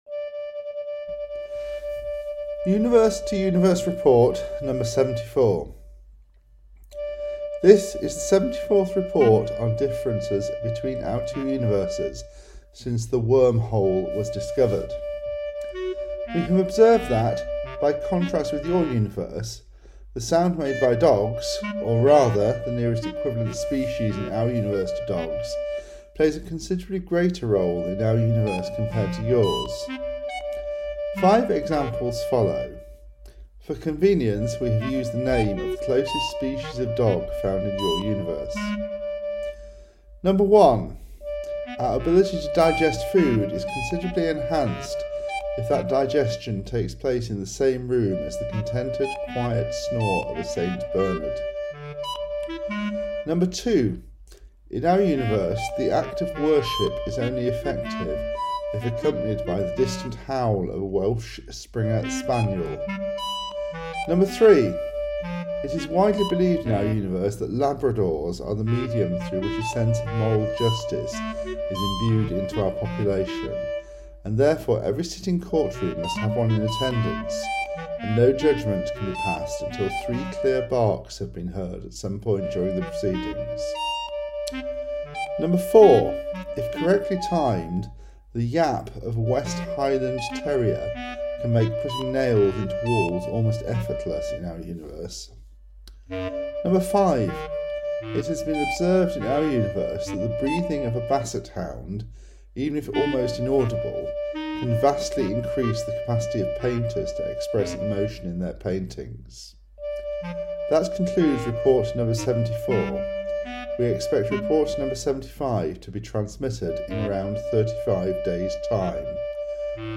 mp3 (computer generated)